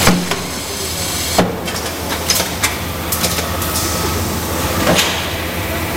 描述：模具，工业，机器，工厂，现场记录，金属，加工
标签： 机器 工厂 金属加工 模具 现场记录 工业
声道立体声